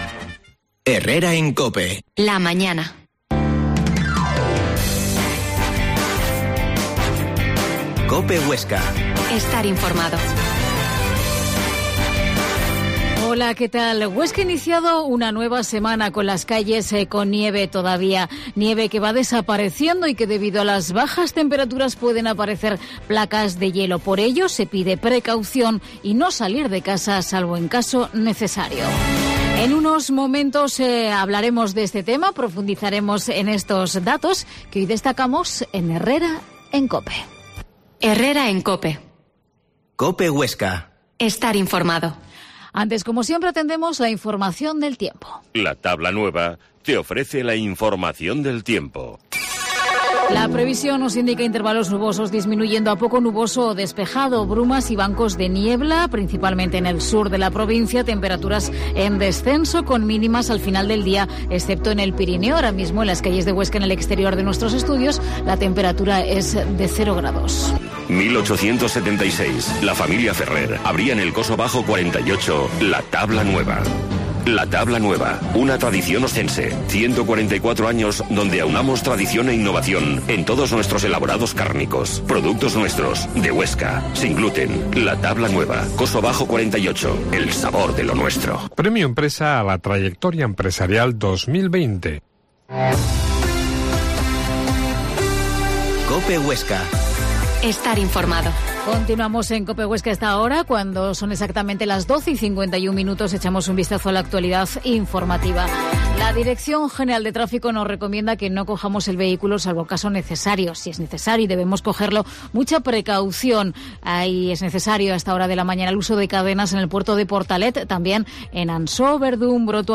Herrera en COPE Huesca 12.50h Entrevista a la concejal de seguridad ciudadana Ana Loriente